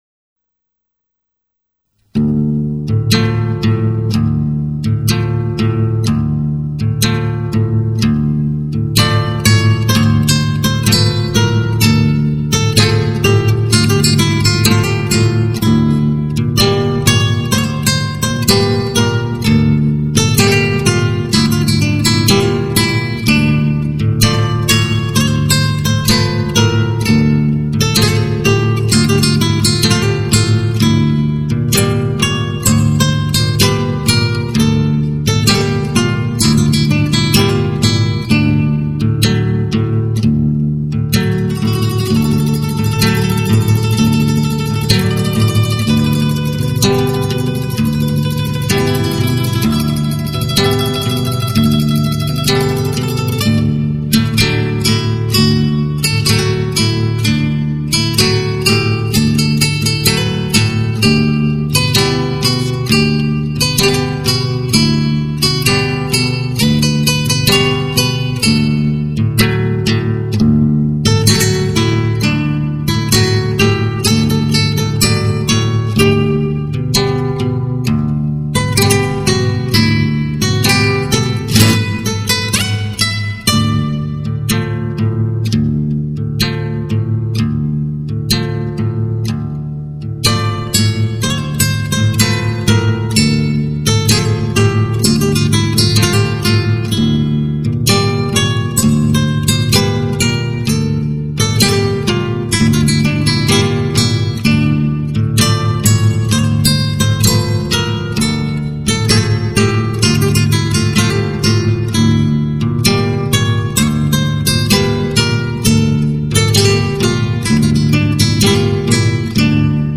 0187-吉他名曲卡门.mp3